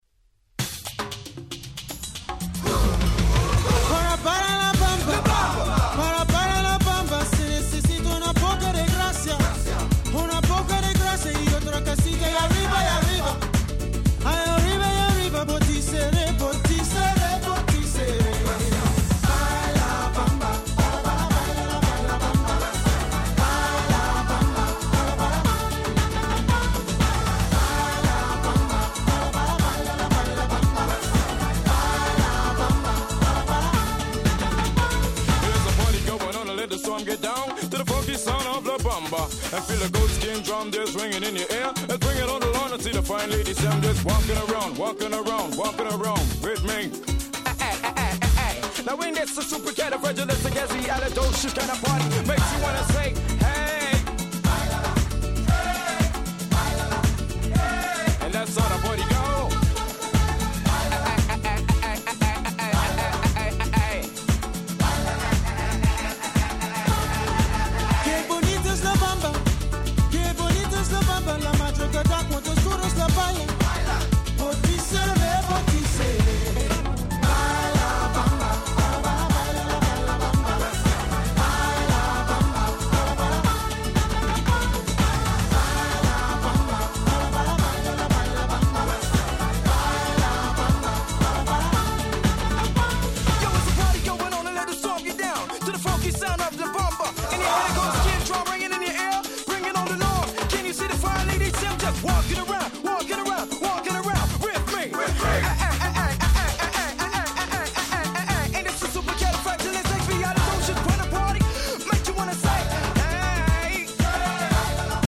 人気曲A-3(アナログはこの盤オンリー！)、GroovyなB-1を始め陽気な感じの良曲多数！